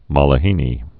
(mälĭ-hēnē)